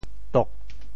诼 潮语发音 Show other regions 潮州 dog4 揭阳 doh4 潮州 0 揭阳 0 Chinese Definitions 恶言咒骂 恶言咒骂，潮汕话叫“咒诼”，也单说“诼”。